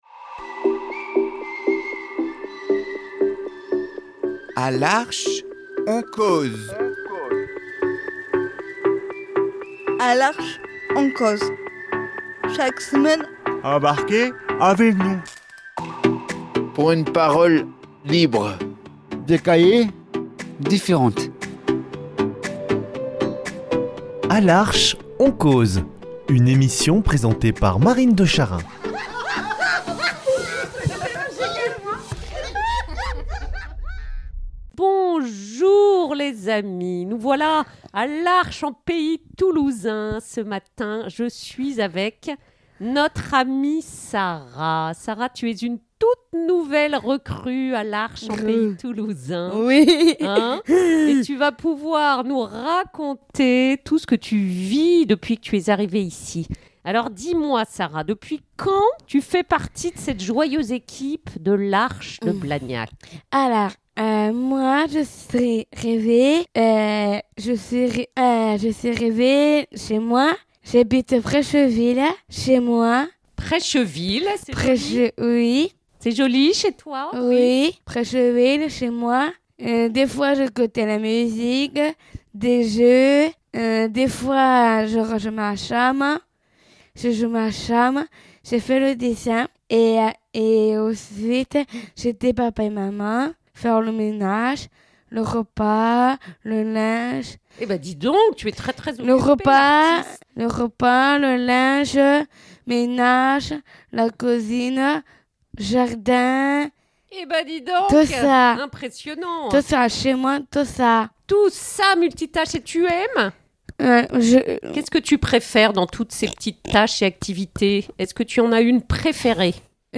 C’est sa première interview, et sa belle énergie nous embarque !